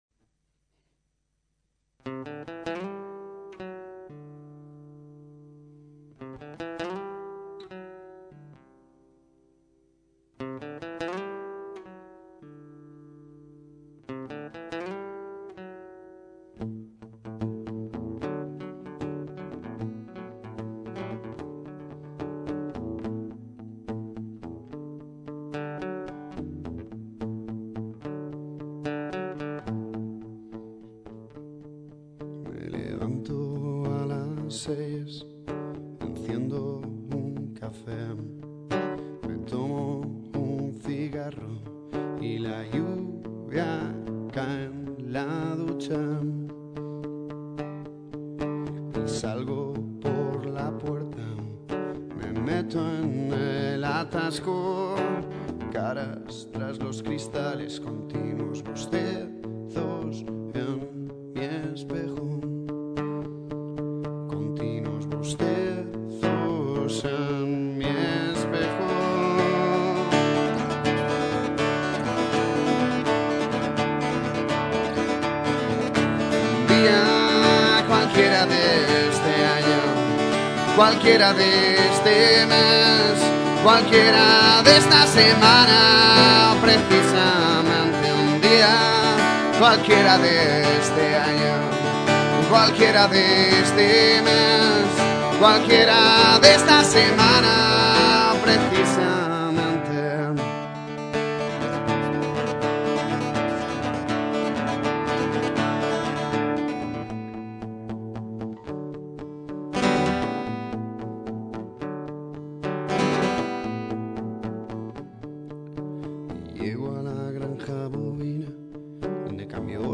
recién compuesta y grabada
1.- La voz me parece que va muy plana.
2.- Los estribillos abusan de la tonalidad de la canción.
3.- Segunda guitarra ya, que el inicio sea en electrica con fuerza, con distorsión y sobre esa base crece la guitarra acústica.